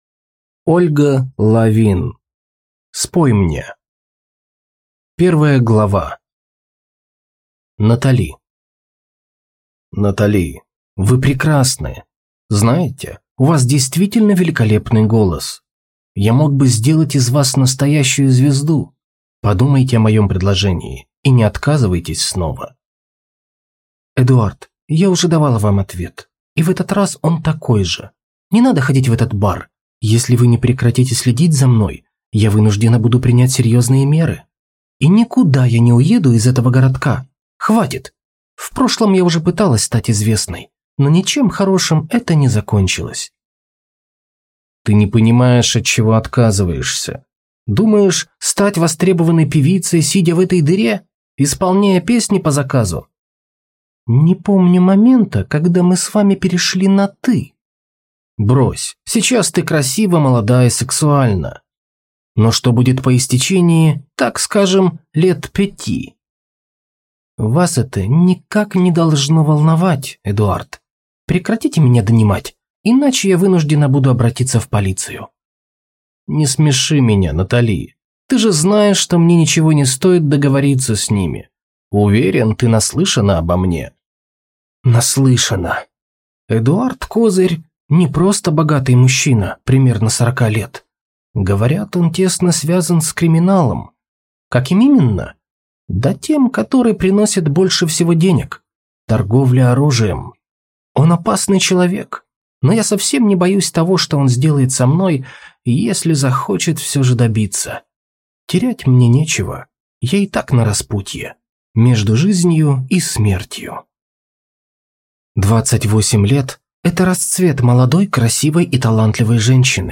Aудиокнига Спой мне